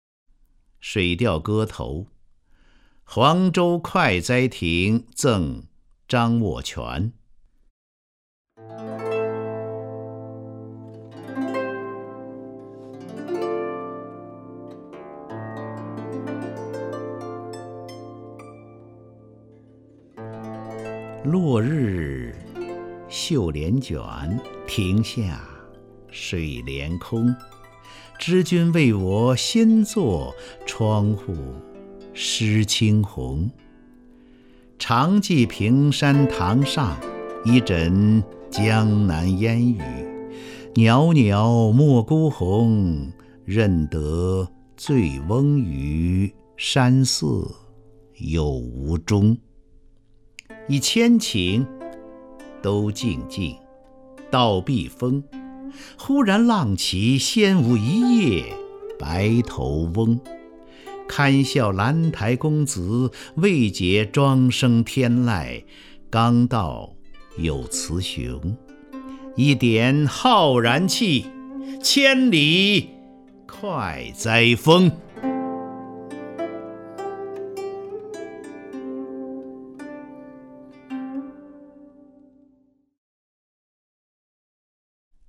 张家声朗诵：《水调歌头·黄州快哉亭赠张偓佺》(（北宋）苏轼)
名家朗诵欣赏 张家声 目录
ShuiDiaoGeTouHuangZhouKuaiZaiTingZengZhangWoQuan_SuShi(ZhangJiaSheng).mp3